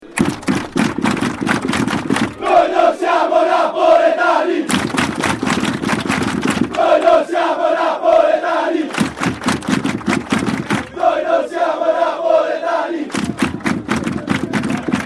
Cori Stadio- Ultras Juventus -Noi Non Siamo Napoletani.mp3